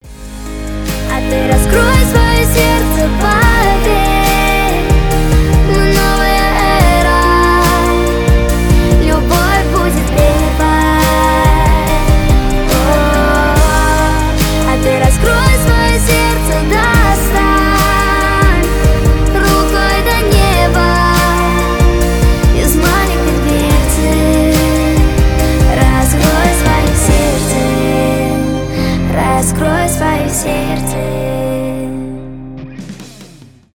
воодушевляющие , детский голос
поп